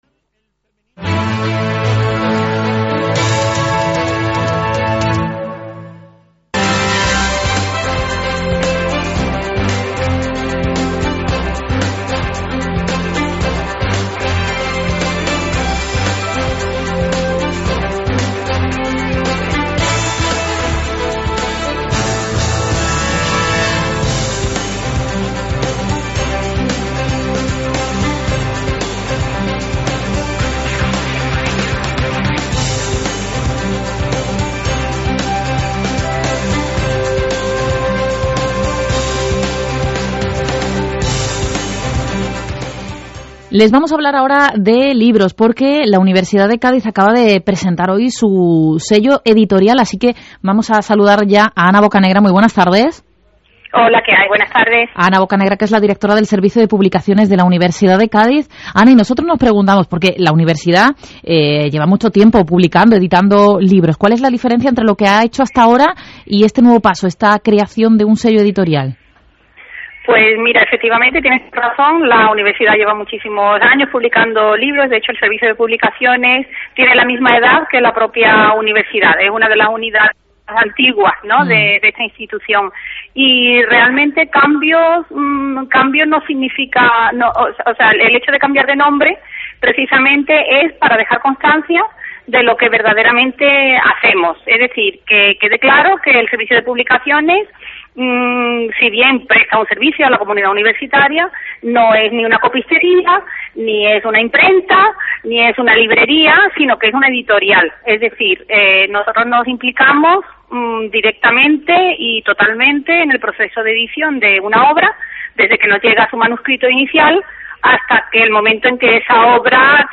CADENA SER CADIZ. Entrevista